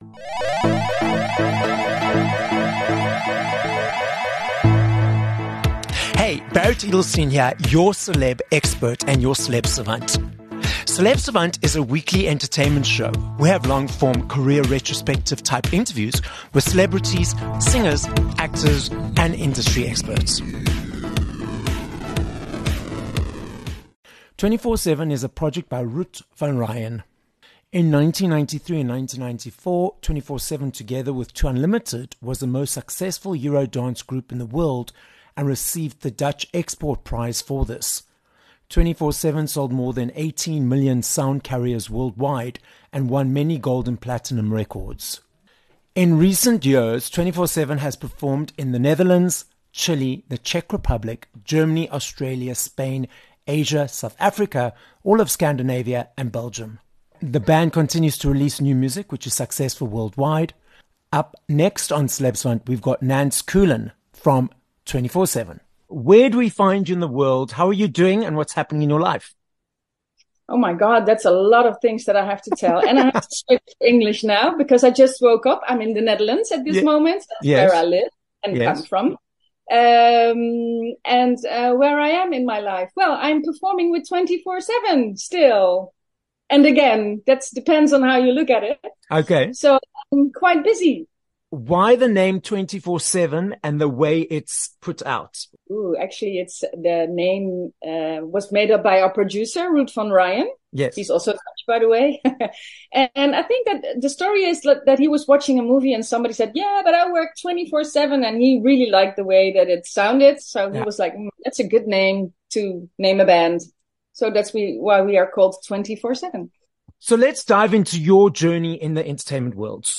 4 Jan Interview with Twenty 4 Seven